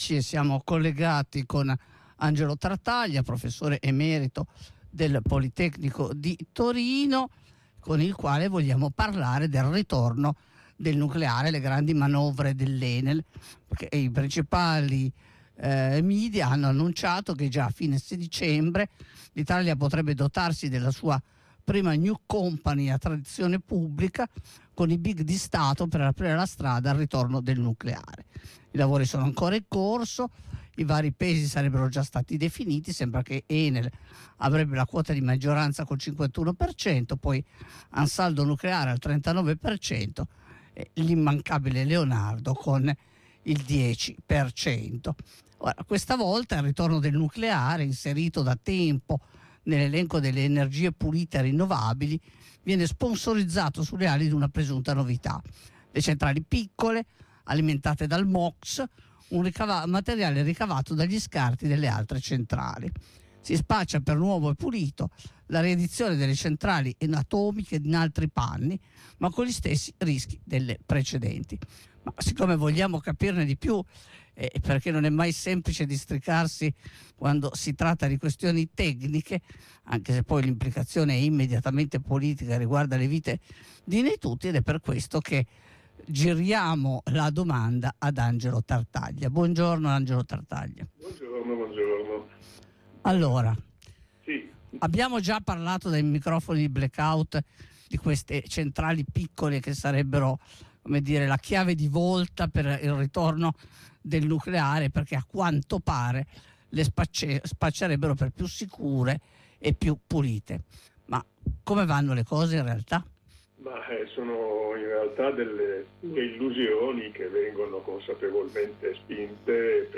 Ascolta la diretta.